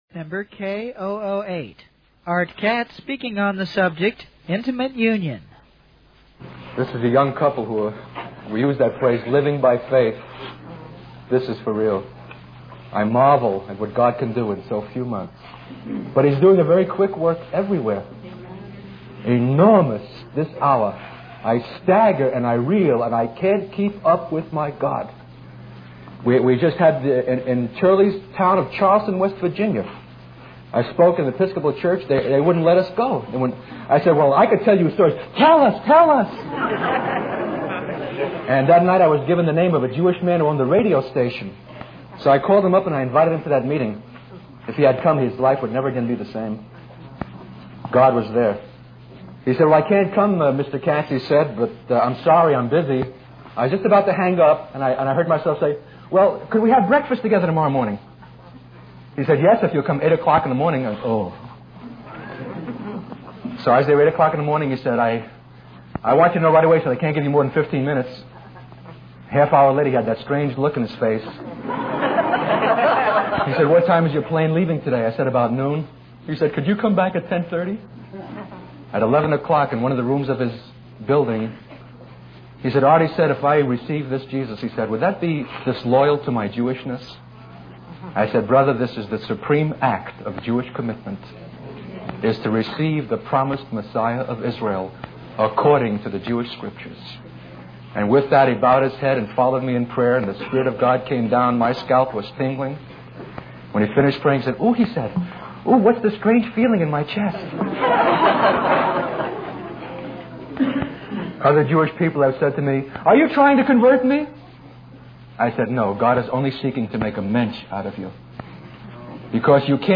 In this sermon, the speaker emphasizes the importance of intimate union with Christ Jesus. He highlights the example of the apostle Paul, who considered everything else as loss compared to the knowledge and experience of being united with Christ.